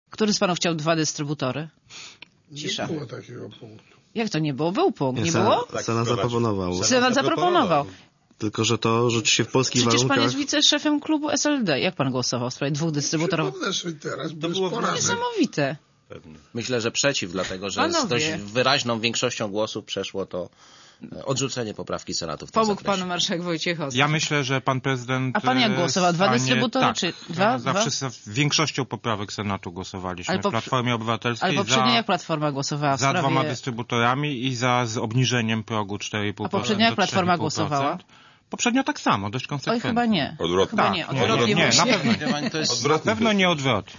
Józef Oleksy - jeden z gości programu Siódmy Dzień Tygodnia w Radiu Zet pytany przez Monikę Olejnik jak głosował, odpowiedział szczerze, że już sobie nie może przypomnieć - jaką decyzję podjał w ostatni czwartek.